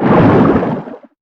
Sfx_creature_hiddencroc_swim_fast_04.ogg